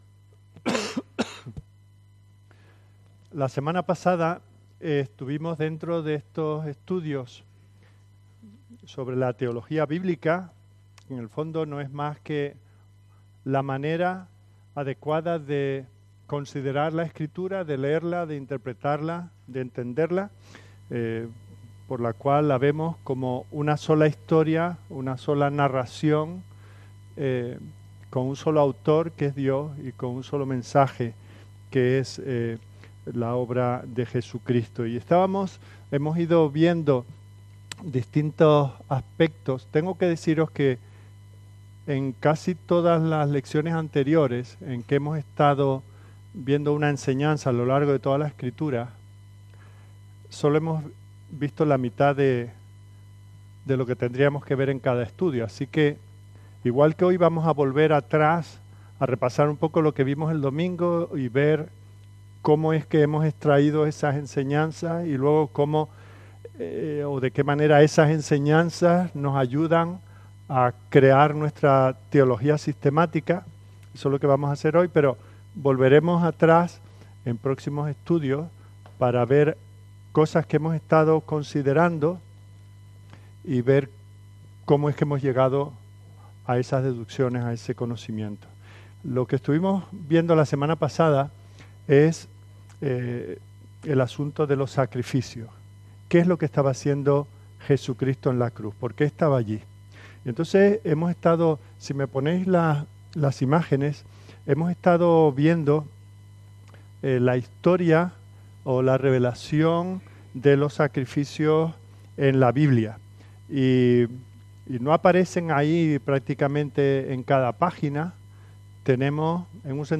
07- La Historia de un pueblo a través de un pacto – (Estudio Bíblico)